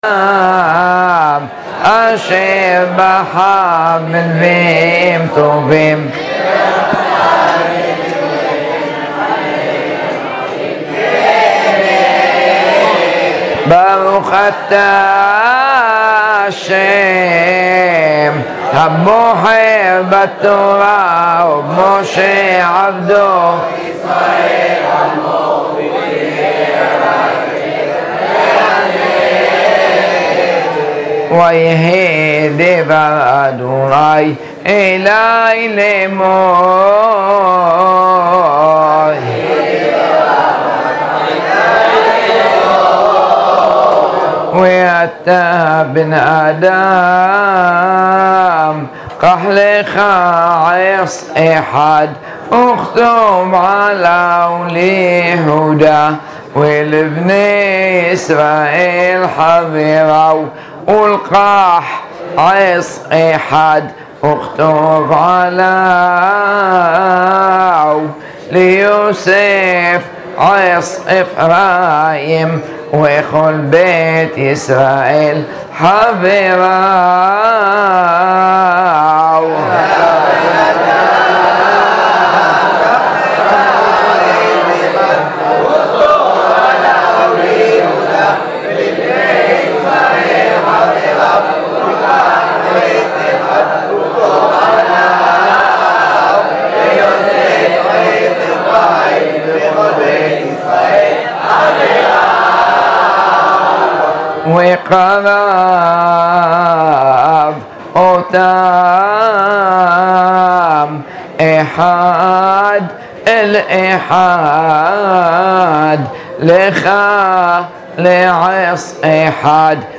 קריאת ההפטרה בטעמים עם התלמידים היקרים עם הכוונה